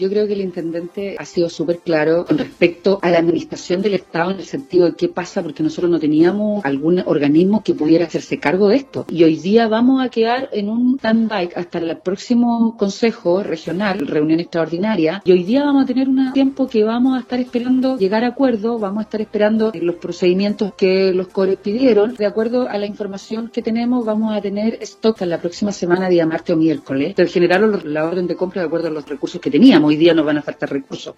En conversación con Radio Sago, la seremi Ingrid Schettino abordó la votación de este jueves donde el Concejo Regional congeló la entrega del dineros para la compra de más cajas de alimentos en la región.